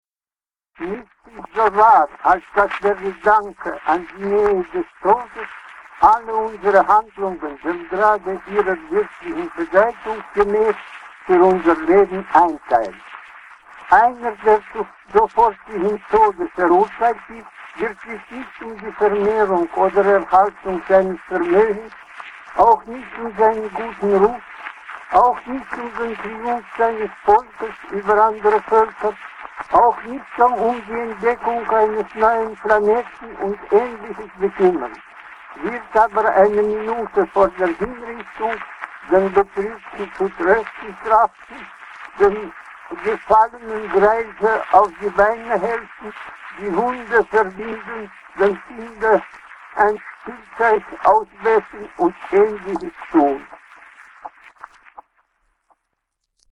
Leo Tolstoy - Original Voice